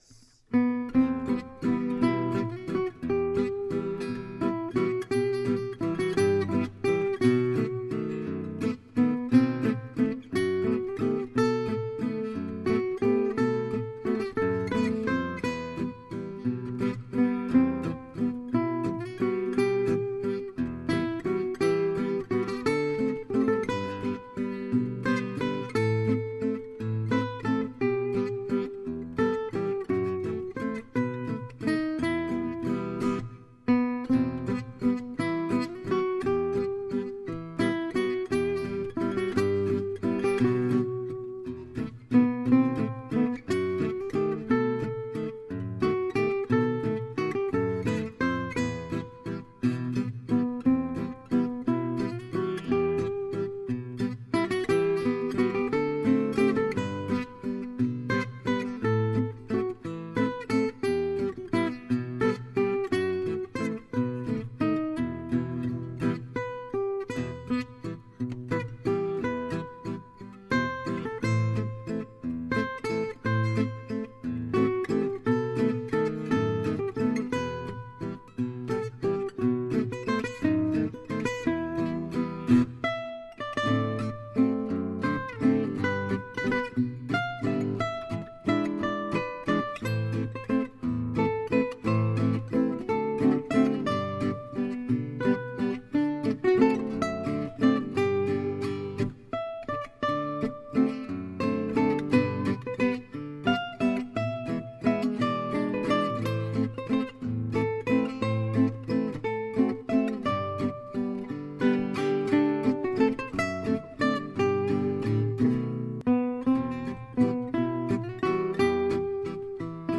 (And forgot to repeat the middle section.)
To my ears the sound is perfect for the piece.
a touch of reverb in the mix.
expectation-waltz-patenotte-grande-bouche.mp3